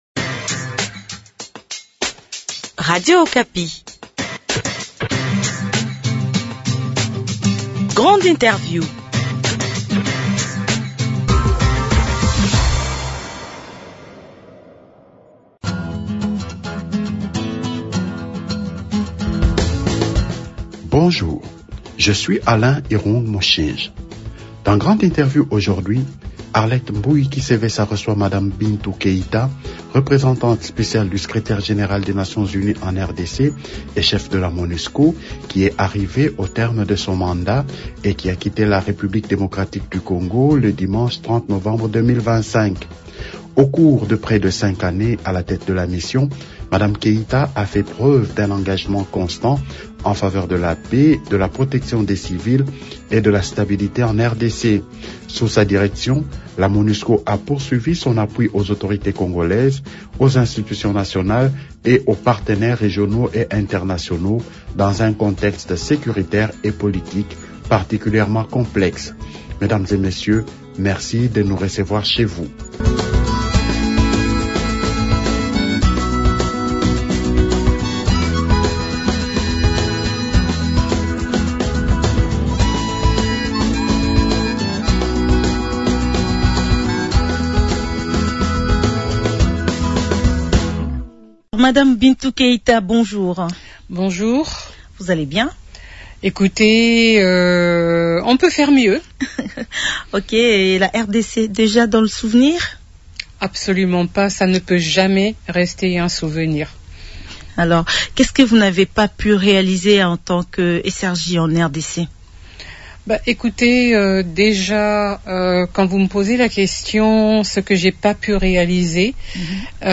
Grande Interview reçoit madame Bintou Keita, Représentante spéciale du Secrétaire général des Nations Unies en RDC et Cheffe de la MONUSCO, qui est arrivée au terme de son mandat et a quitté la République démocratique du Congo le dimanche 30 novembre 2025. Au cours de près de cinq années à la tête de la Mission, Mme Keita a fait preuve d’un engagement constant en faveur de la paix, de la protection des civils et de la stabilité en RDC.